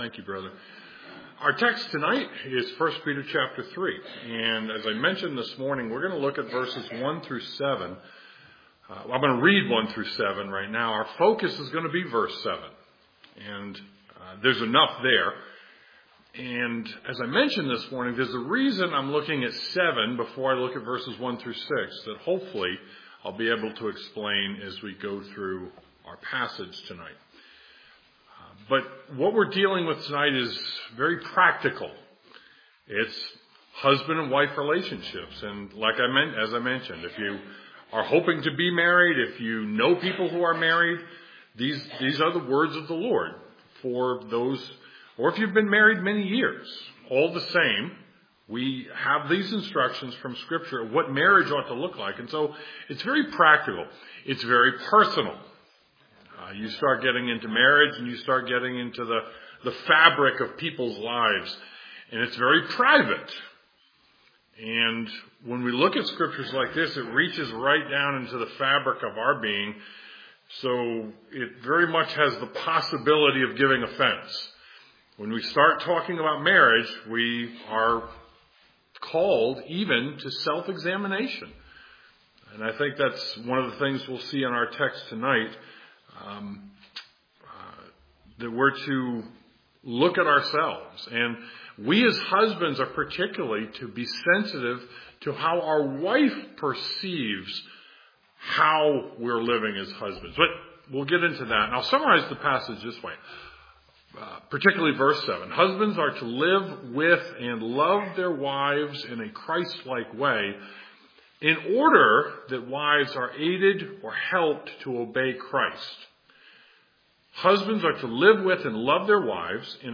1 Peter 3:7 Service Type: Sunday Evening 1 Peter 3:7 Husbands are to live with and love their wives in a Christ-like way.